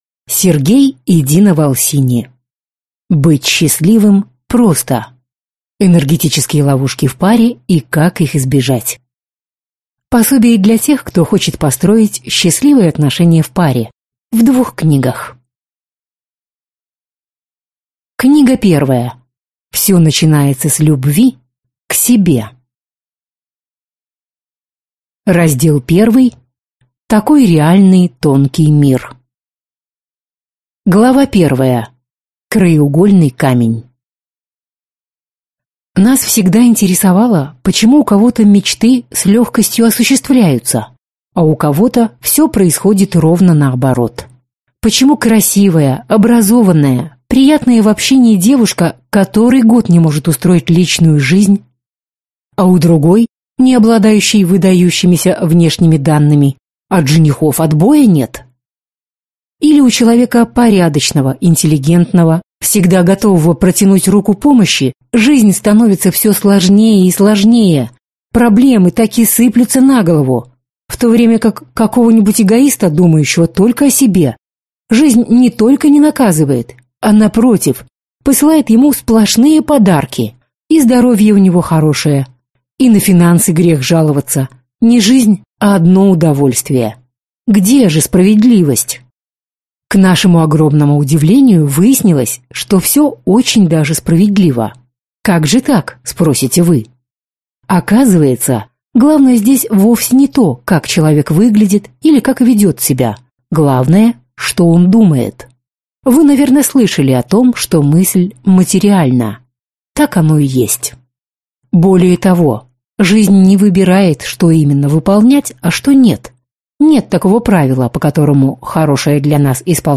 Аудиокнига Быть счастливым просто! Энергетические ловушки в паре и как их избежать | Библиотека аудиокниг